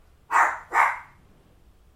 dog bark
this is a dog barking to be used in my game